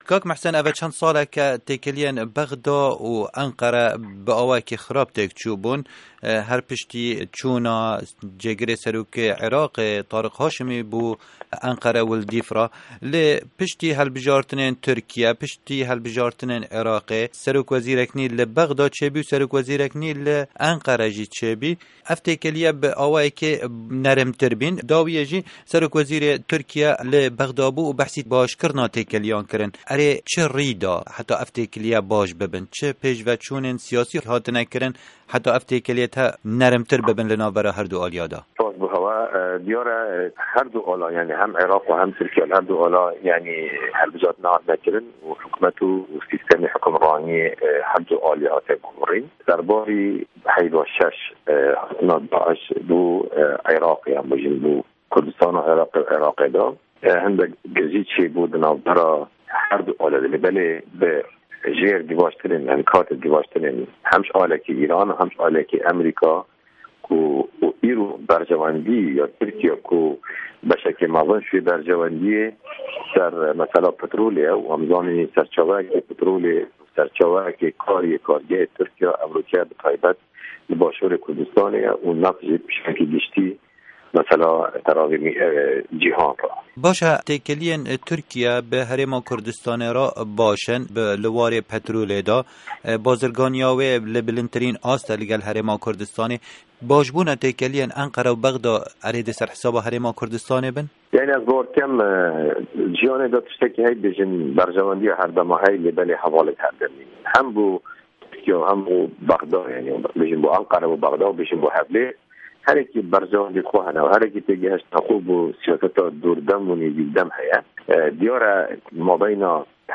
hevpeyvin digel Muhsin Osman